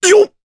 Gau-Vox_Jump_jp.wav